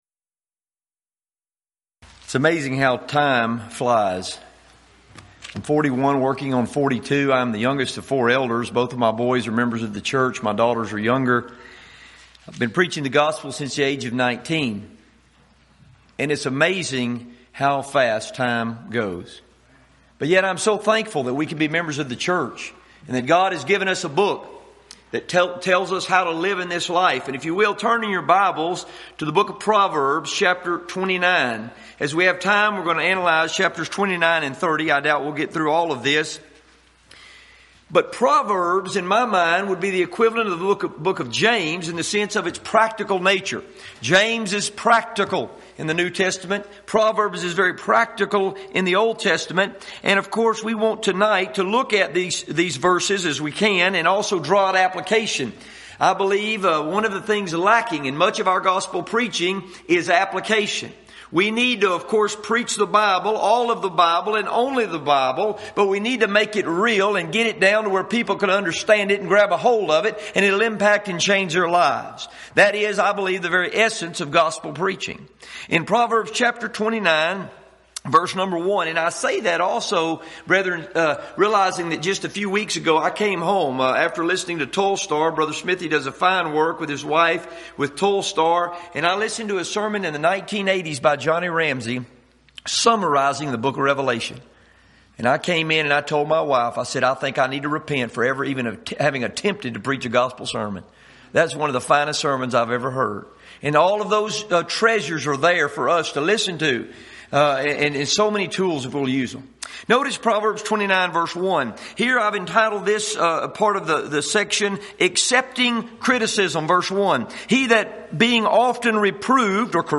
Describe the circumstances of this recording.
Event: 13th Annual Schertz Lectures